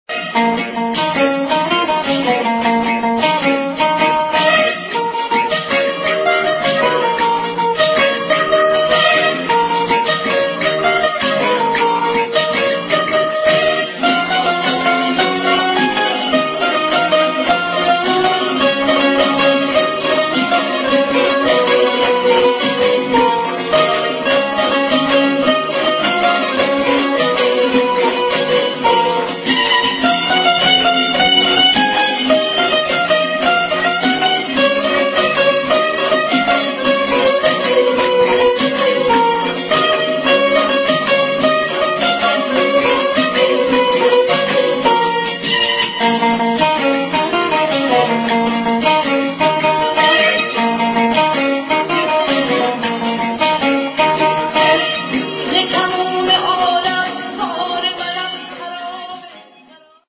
نسخه بی کلام